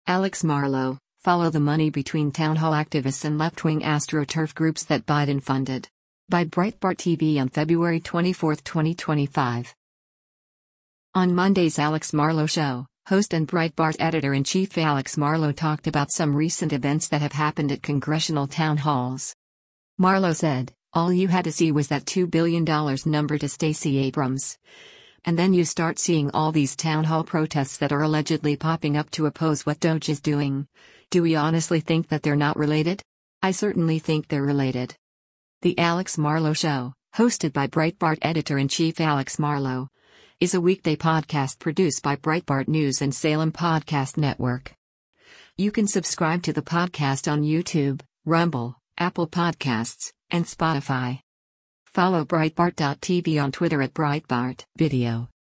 On Monday’s “Alex Marlow Show,” host and Breitbart Editor-in-Chief Alex Marlow talked about some recent events that have happened at congressional town halls.